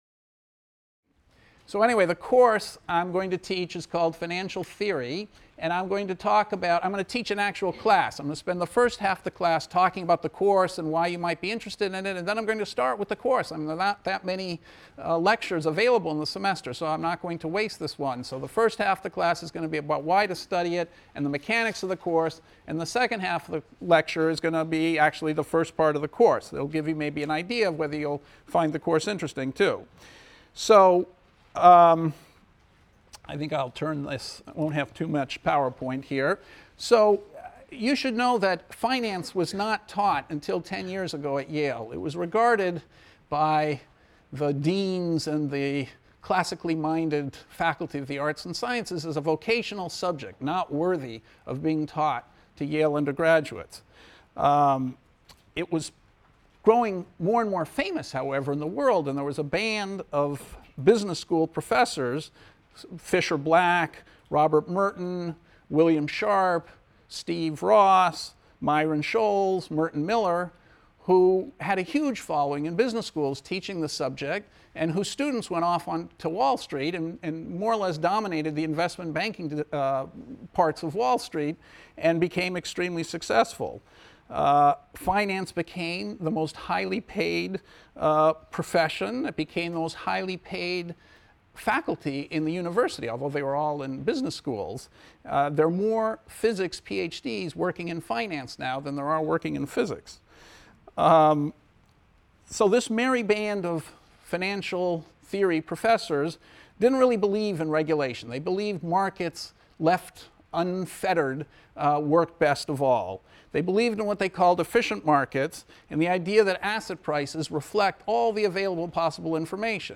ECON 251 - Lecture 1 - Why Finance?